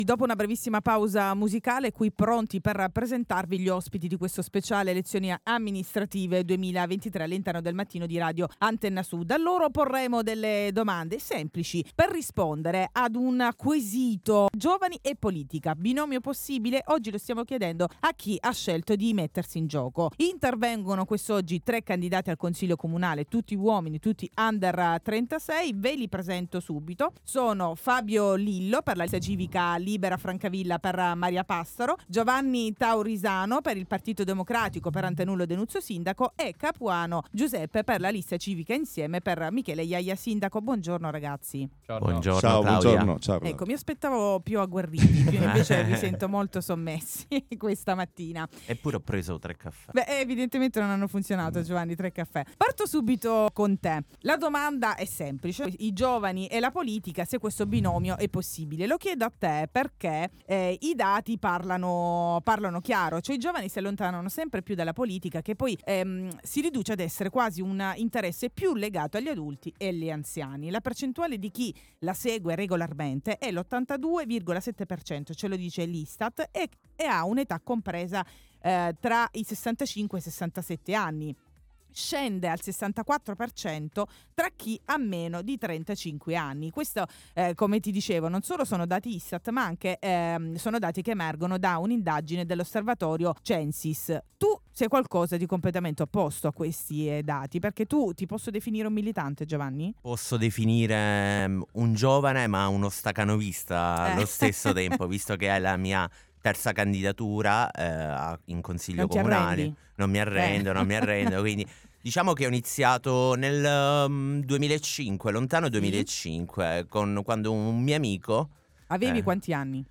In studio, tre candidati al consiglio comunale, tutti under 36